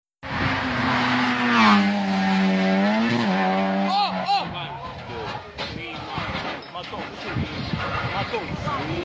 Crash Peugeot 106 Rallye 😯 Sound Effects Free Download